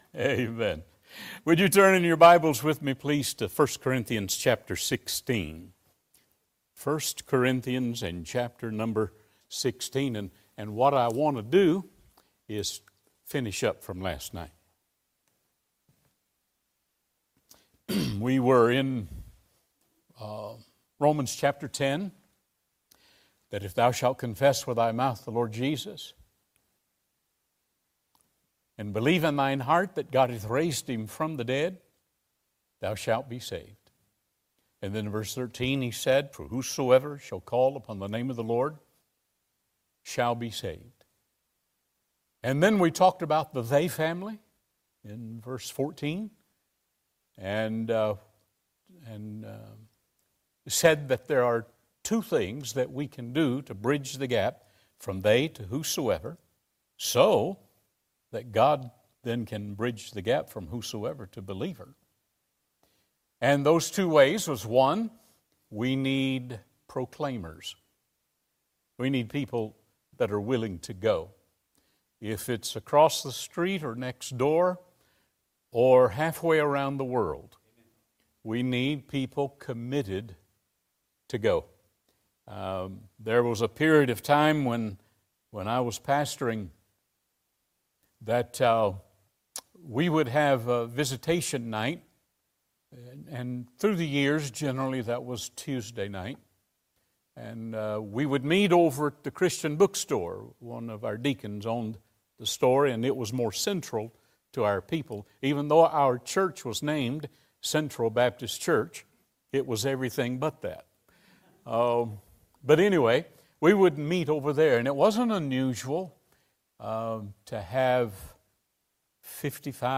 2 Cor. 8:1-15 Service Type: Midweek Service Topics